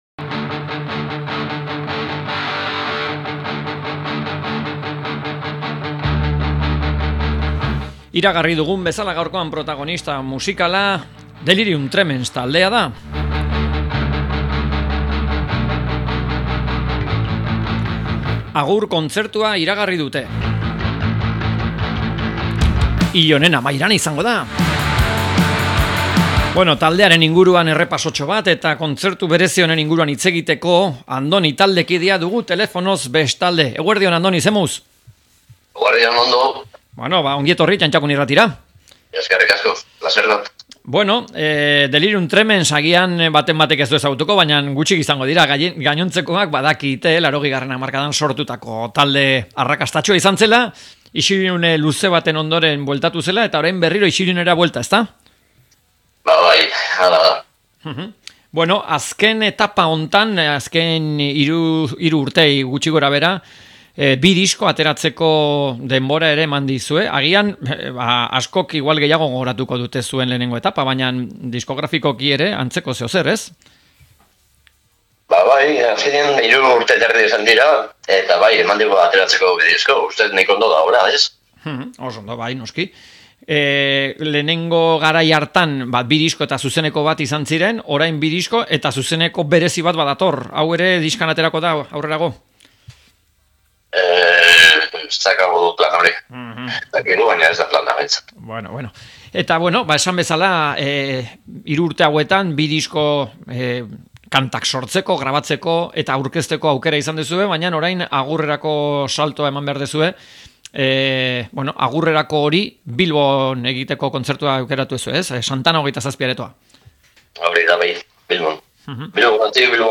Delirium Tremens-i elkarrizketa